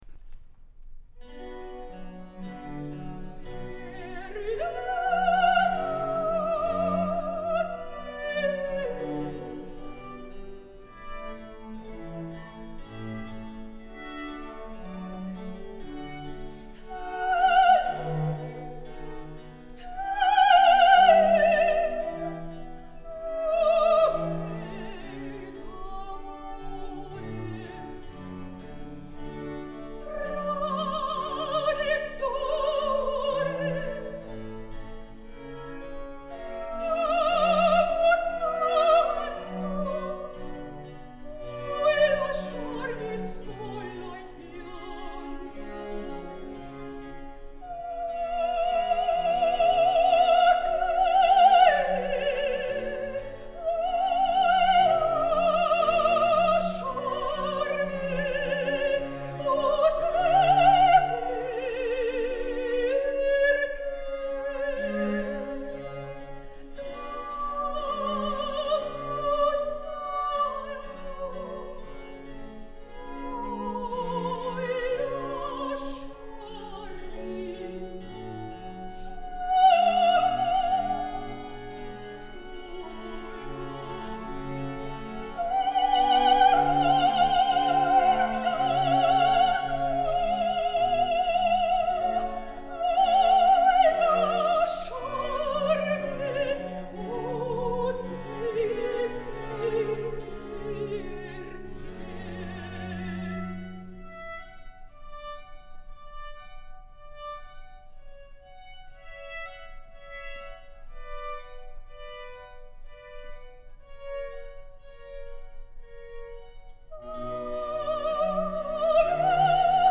Soprano
"A silvery-voiced singer"
aired on WWFM